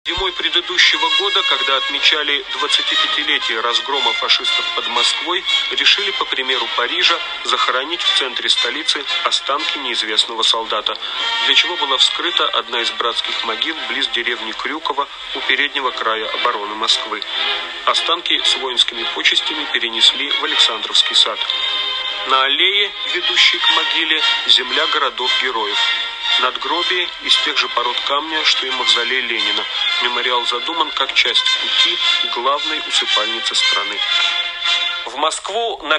По всей видимости, это аранжировка песни.